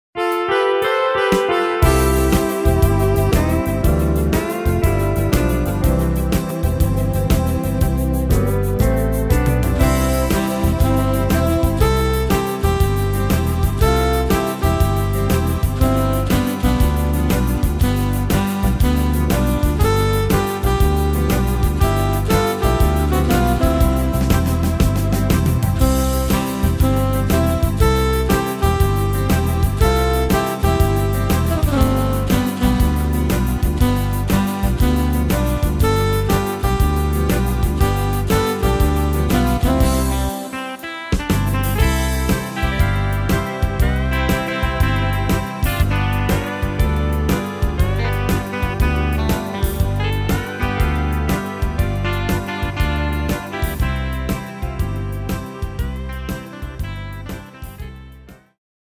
Tempo: 120 / Tonart: C-Dur